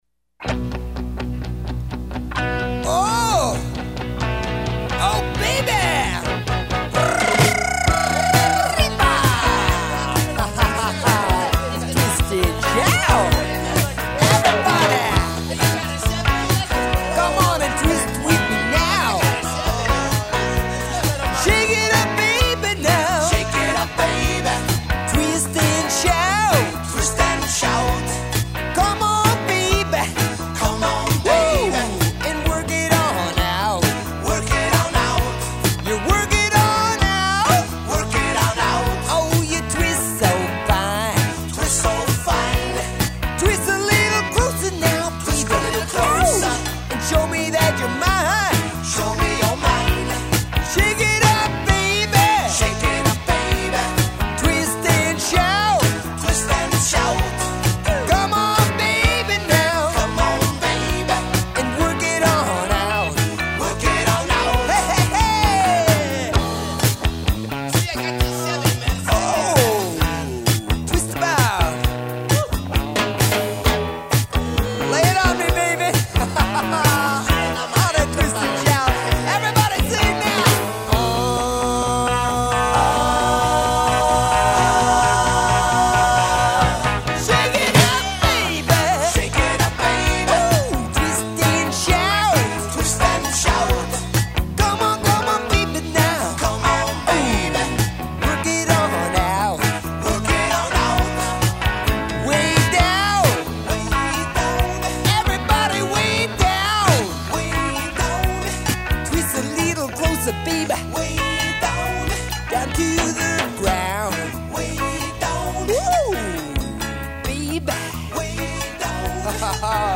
Rhythm guitar
Drummer
Bass